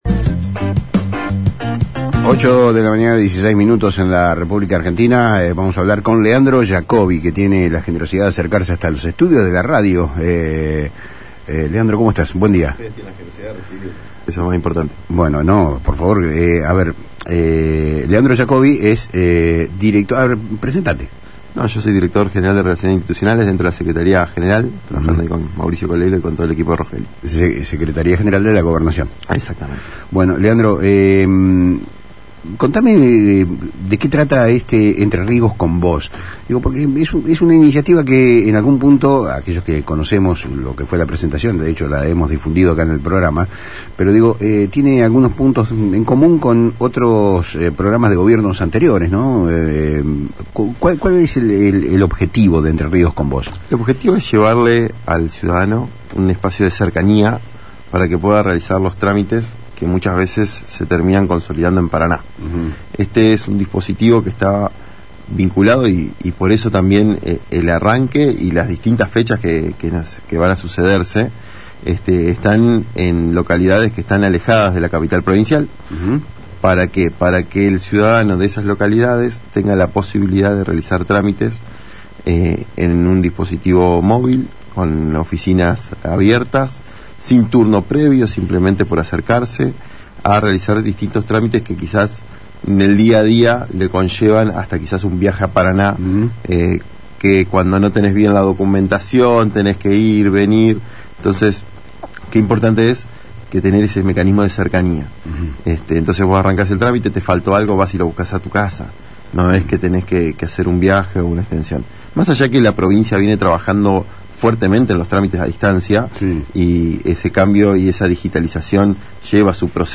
Leandro Jacobi, director de Relaciones Institucionales del Gobierno de Entre Ríos que depende de la Secretaría General de la Gobernación de Entre Ríos, visitó los estudios de FM Litoral para hablar, en Palabras Cruzadas, sobre el programa «Entre Ríos con Vos», una iniciativa que busca acercar los servicios del Estado a los ciudadanos en todo el territorio provincial.